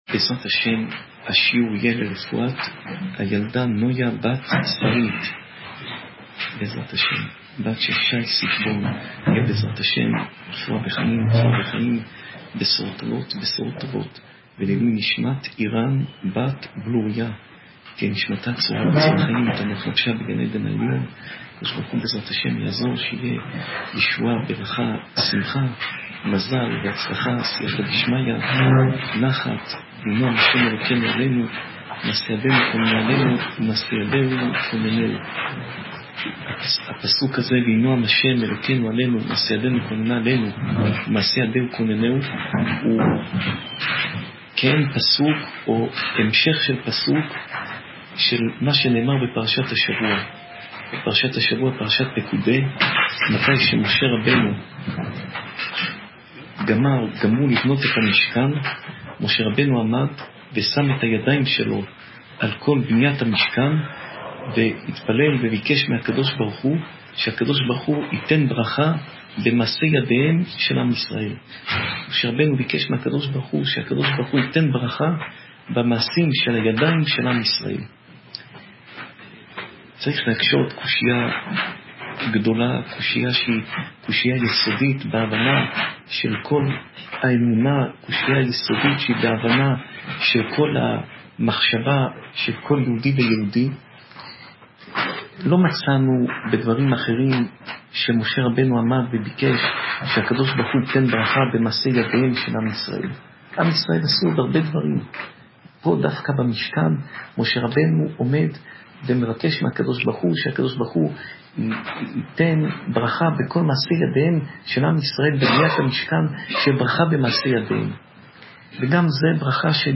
שעורי תורה